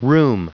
Prononciation du mot rheum en anglais (fichier audio)
Prononciation du mot : rheum